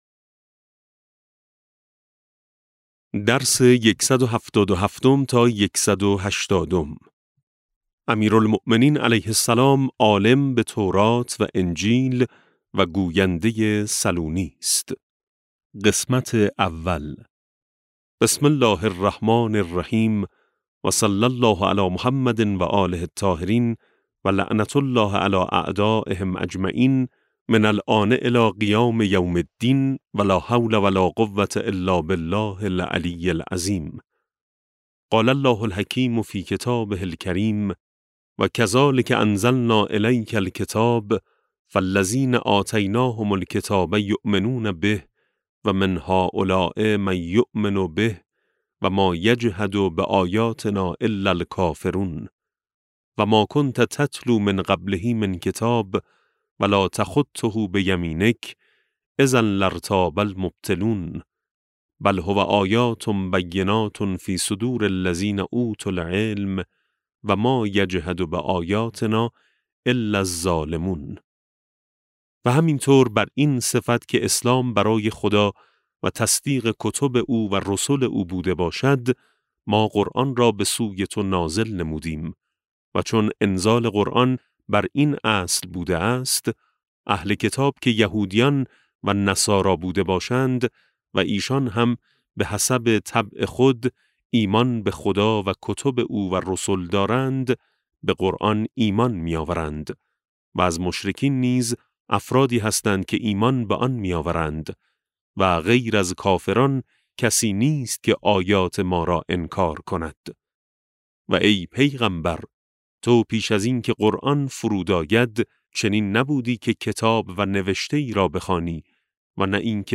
کتاب صوتی امام شناسی ج12 - جلسه12